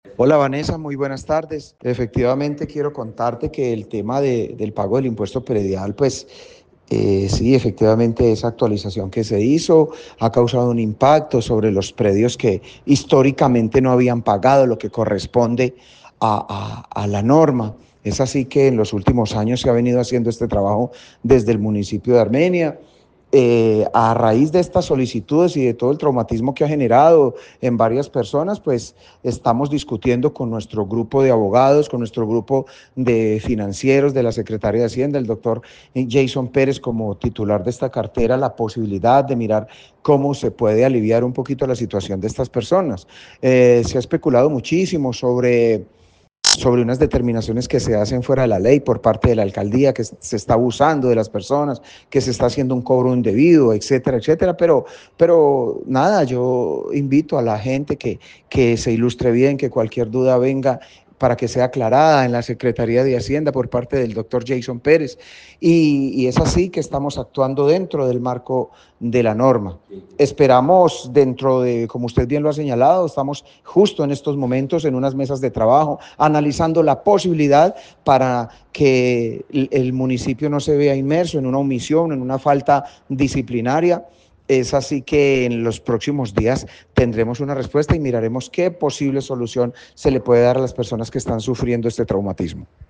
James Padilla, alcalde de Armenia